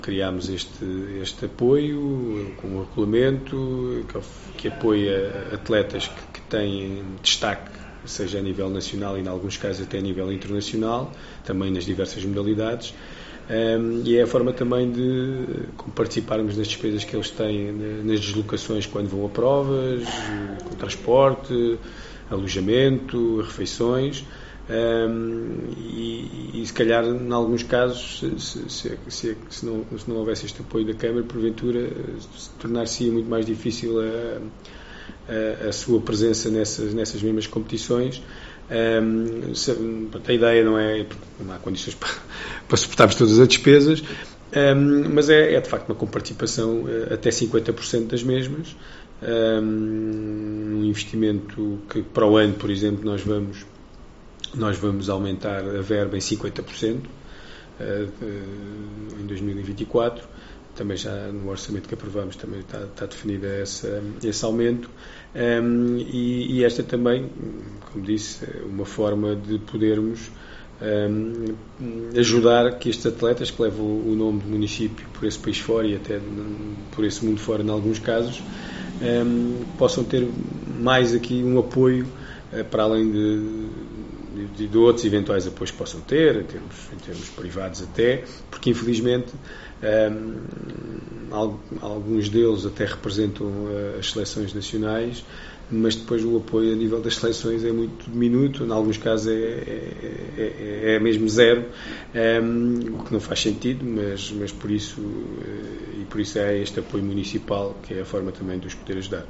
Declarações do Presidente da Câmara Municipal de Santiago do Cacém, Álvaro Beijinha